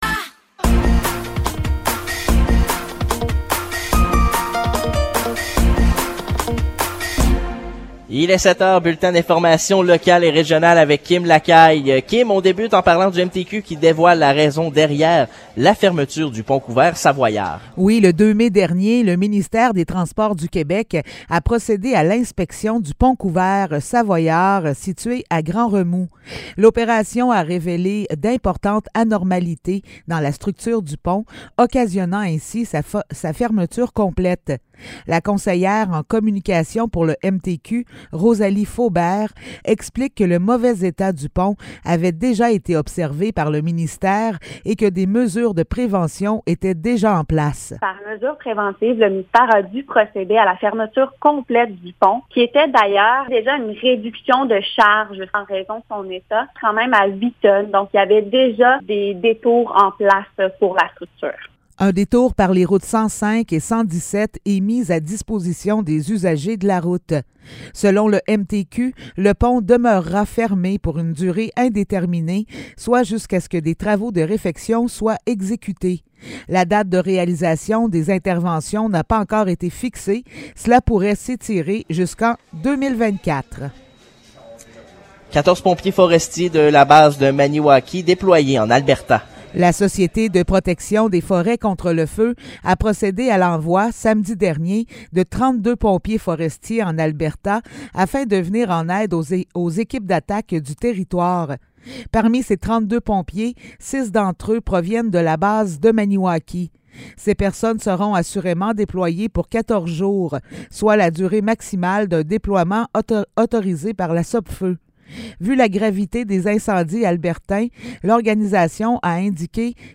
Nouvelles locales - 10 mai 2023 - 7 h